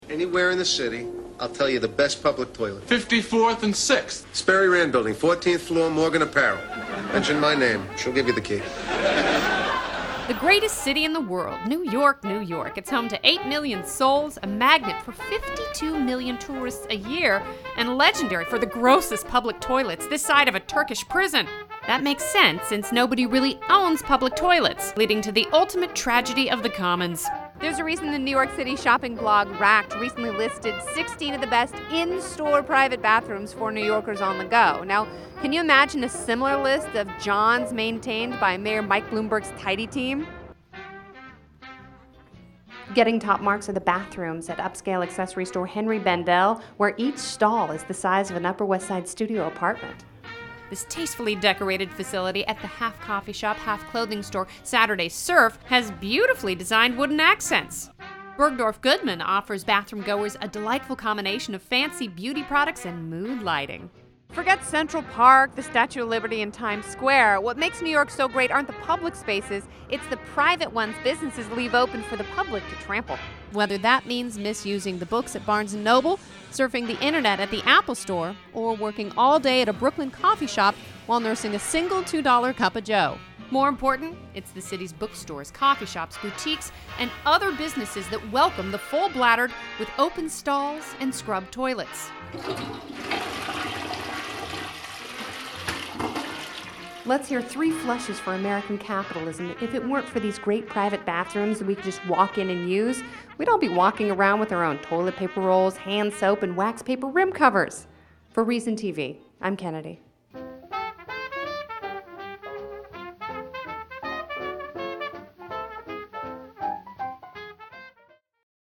Hosted by Kennedy.